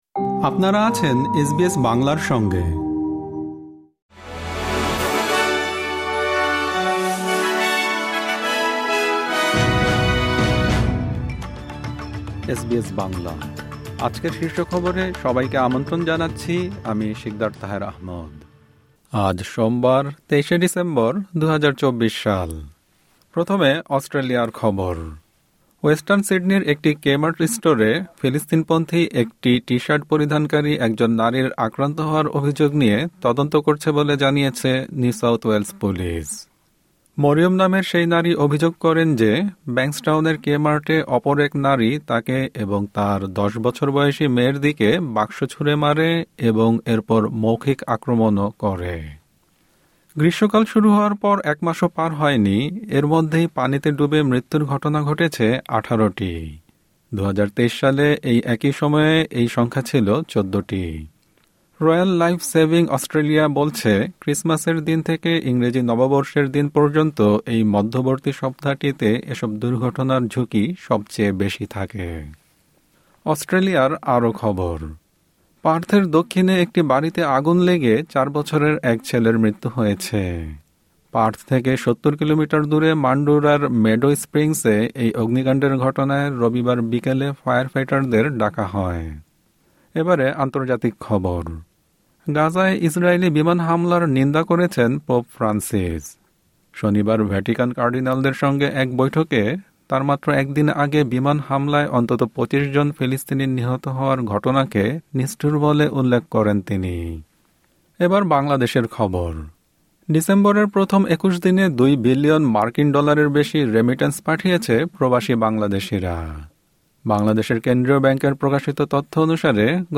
আজকের শীর্ষ খবর ওয়েস্টার্ন সিডনির একটি কে-মার্ট স্টোরে ফিলিস্তিন-পন্থী একটি টি-শার্ট পরিধানকারী একজন নারীর আক্রান্ত হওয়ার অভিযোগ নিয়ে তদন্ত করছে বলে জানিয়েছে নিউ সাউথ ওয়েলস পুলিস। গ্রীষ্মকাল শুরু হওয়ার পর একমাসও পার হয় নি, এর মধ্যেই পানিতে ডুবে মৃত্যুর ঘটনা ঘটেছে ১৮টি।